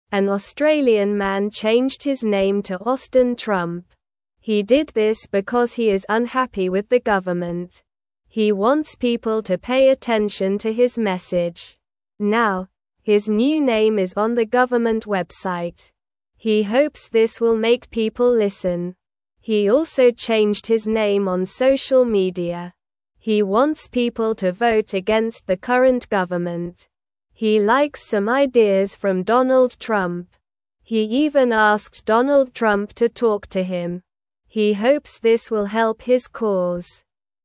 Slow
English-Newsroom-Beginner-SLOW-Reading-Man-Changes-Name-to-Austin-Trump-for-Attention.mp3